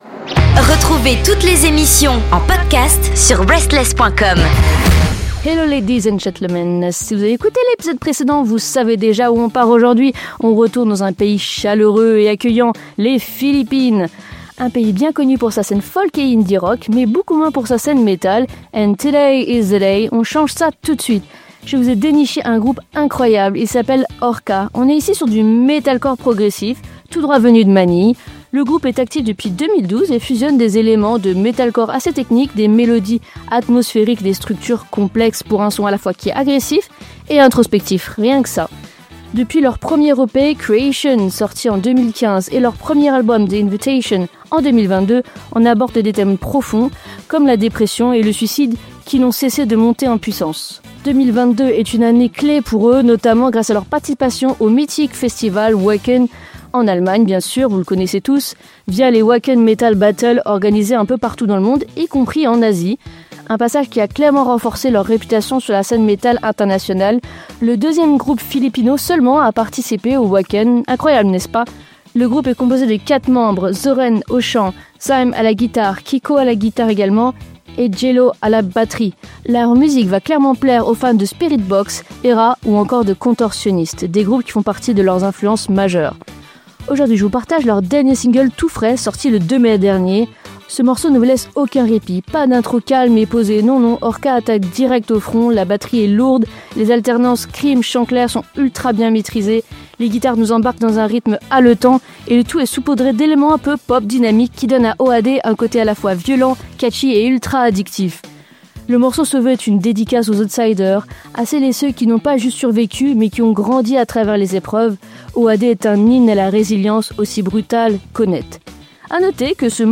Ce groupe commence à se faire connaître même à l’international grâce à son métal puissant, technique et introspectif.
Leur dernier single « O.A.D. » est une claque : entre intensité brute, tension émotionnelle et riffs percutants… et ce petit côté pop qui rend le morceau totalement addictif !